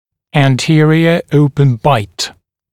[æn’tɪərɪə ‘əupən baɪt][эн’тиэриэ ‘оупэн байт]передний открытый прикус